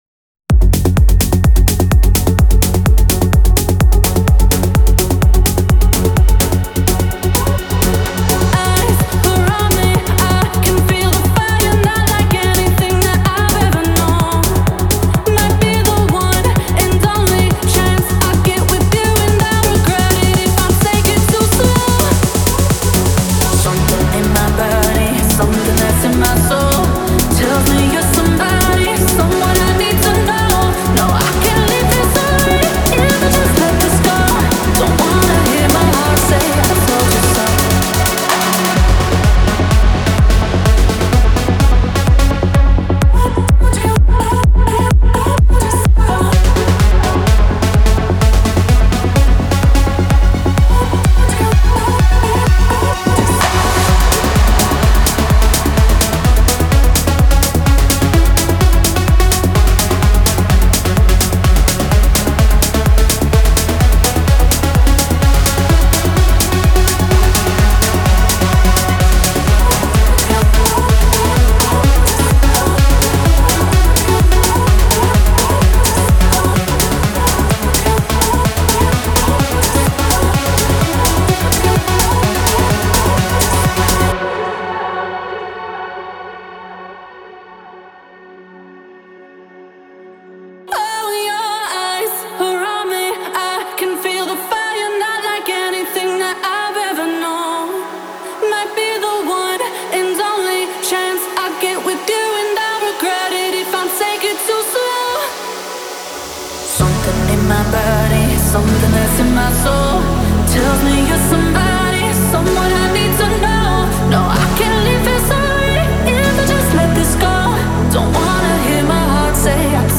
• Жанр: Techno